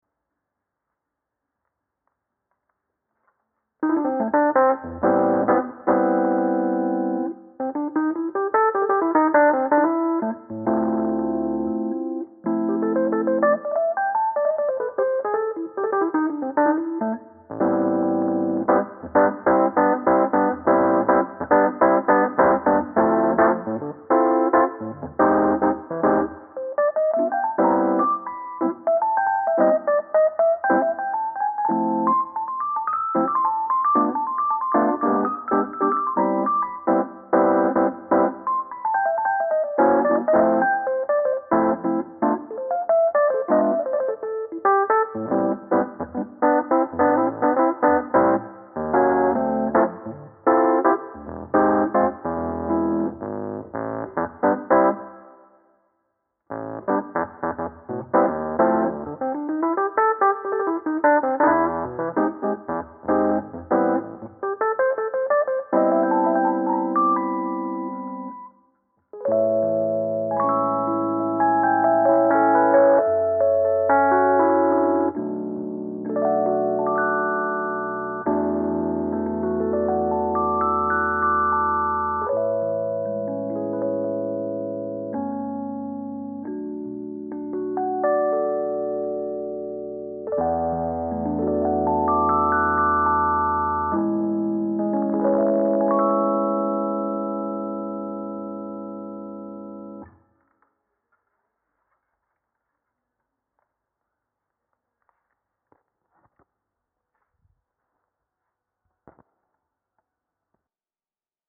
sx-rhodes take2.mp3